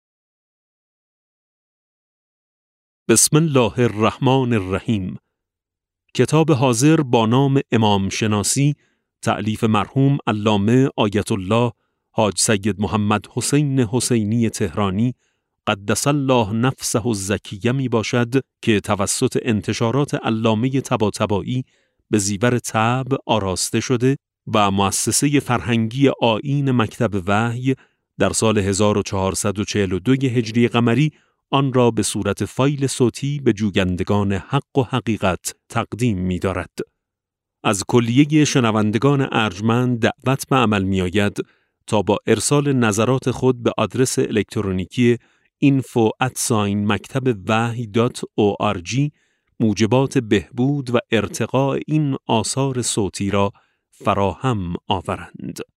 کتاب صوتی امام شناسی ج 16 و17 - جلسه0